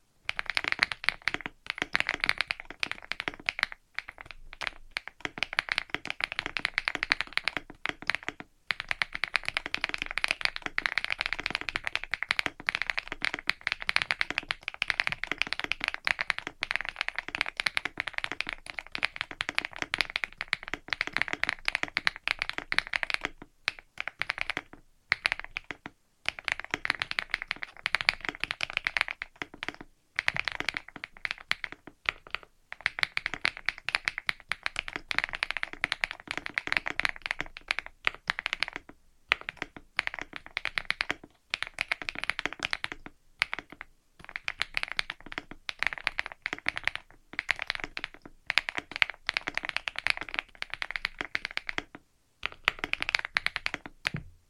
The KiiBoom Moonshadow V2 comes with a set of Cherry profile PBT keycaps, with a Dolch-like colouration (grey with light blue accents) in the silver version, as well as KiiBoom Flick linear switches.
The sound is quite muted, which I especially like as it means I can use the keyboard without everyone else hearing it from kilometres away; it is relatively low in pitch and has a higher-pitched component when you bottom the switches. The use of multiple layers of foam seems to work very well in making the keyboard relatively silent.